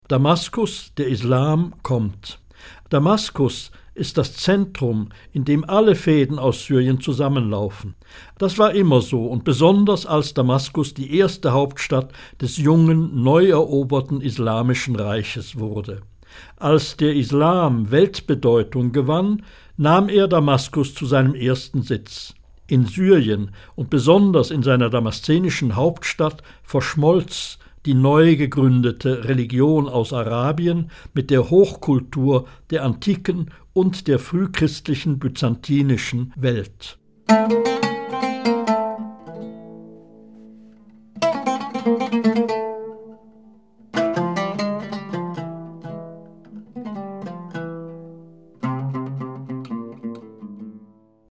Hörbuch Syrien